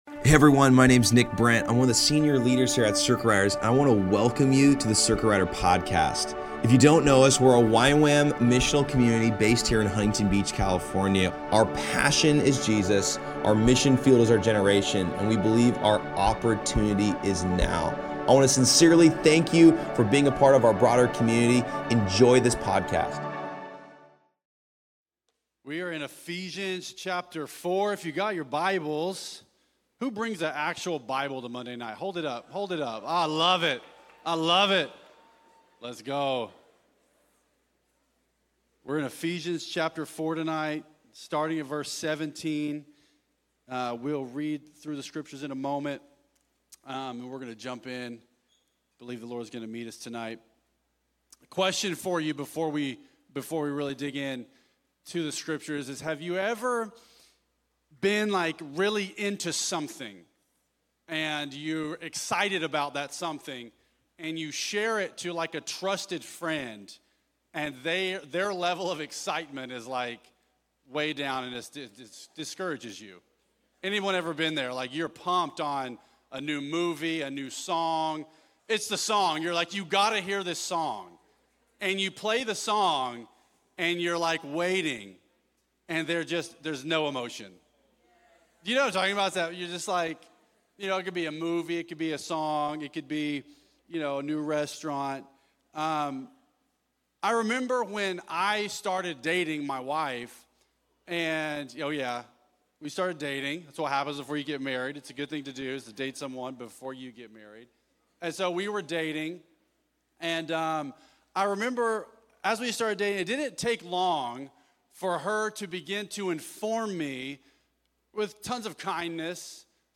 He ends the message with an altar call, inviting people into a deeper and more transformative relationship with Jesus—whether through salvation or by acting on the specific area God is stirring in their heart.